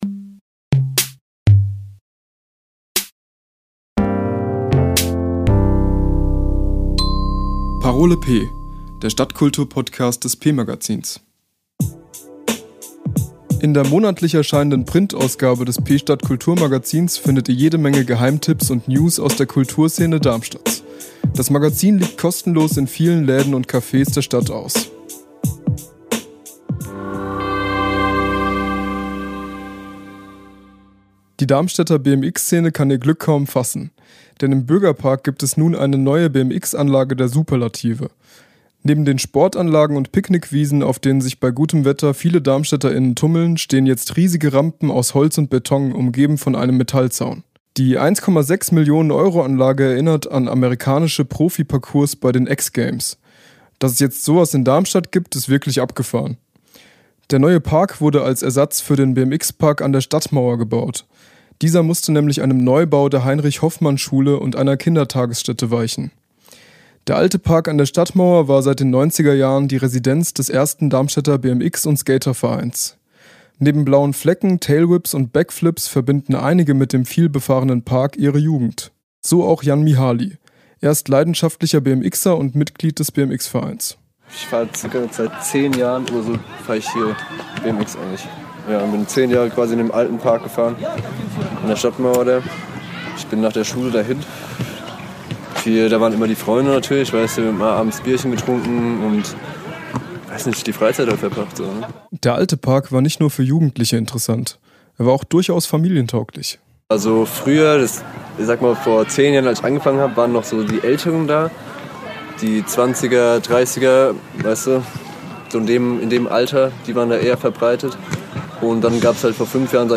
Die neue BMX-Anlage im Bürgerpark sorgt für große Freude in der Szene. Wir haben Stimmen aus dem Geschehen rund um das neue Eldorado für Waghalsige eingefangen.